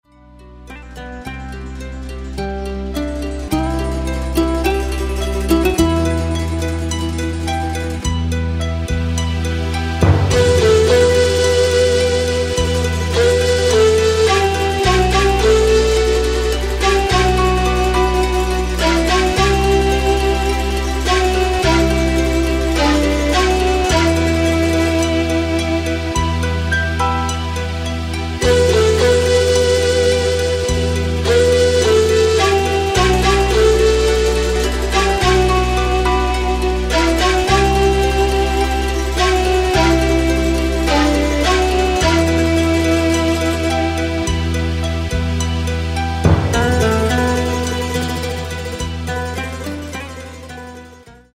Takt:          4/4
Tempo:         106.00
Tonart:            Em
Flöten-Fantasien Instrumental aus dem Jahr 2021!